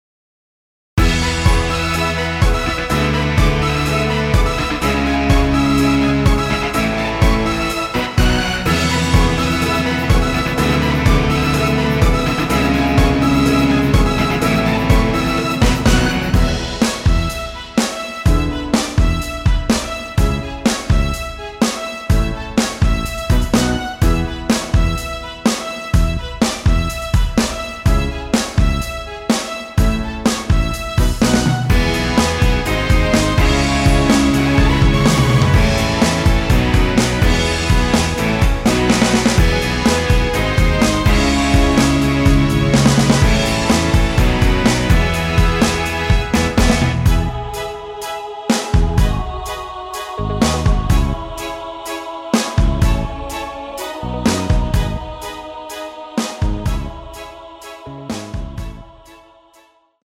엔딩이 페이드 아웃이라 엔딩을 만들어 놓았습니다.
앞부분30초, 뒷부분30초씩 편집해서 올려 드리고 있습니다.
중간에 음이 끈어지고 다시 나오는 이유는